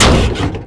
car_heavy_8.wav